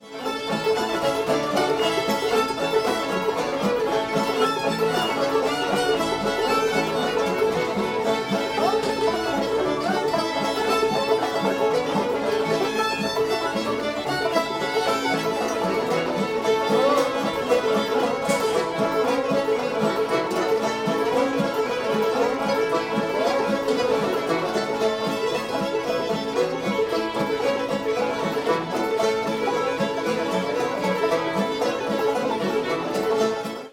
cripple creek [A]